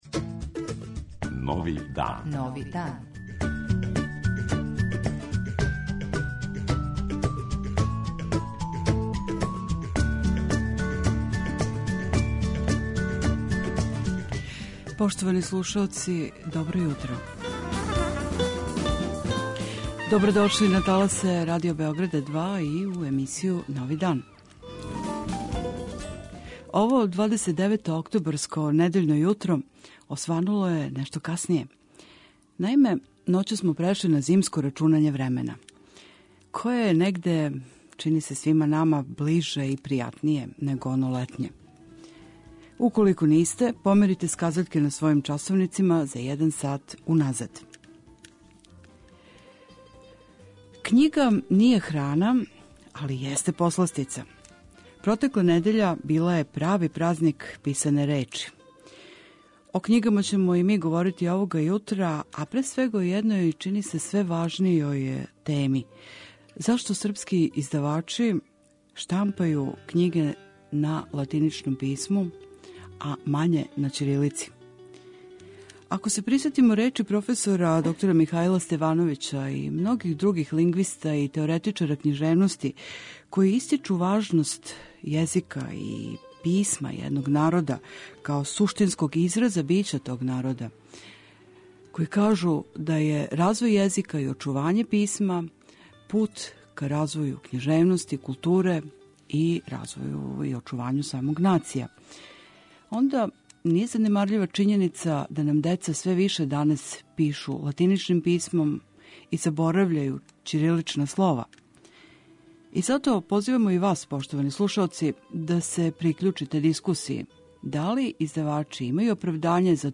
62. Међународни сајам књига затвара се у недељу 29. октобра, па је то прилика да сумирамо утиске, чујемо резултате, као и занимљиве разговоре и репортерске записе забележене током протекле недеље на Сајму.